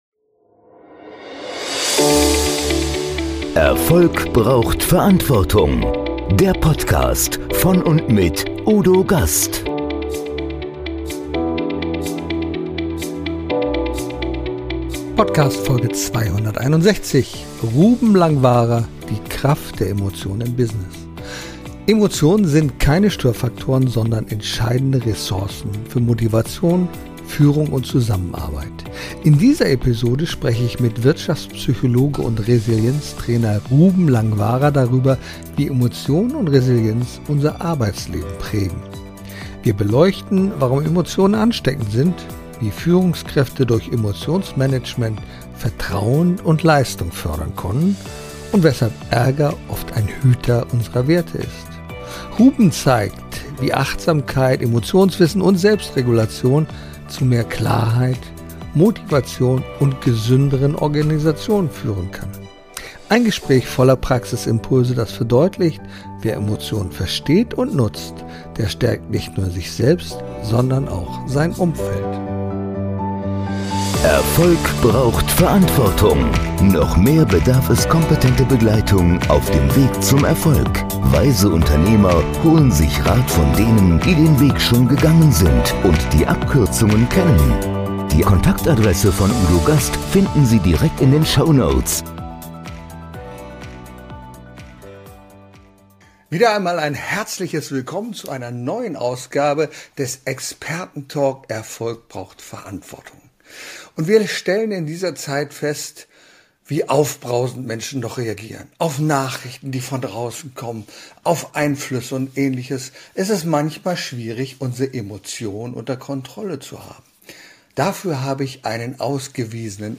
Ein Gespräch voller Praxisimpulse, das verdeutlicht: Wer Emotionen versteht und nutzt, stärkt nicht nur sich selbst, sondern auch sein Umfeld.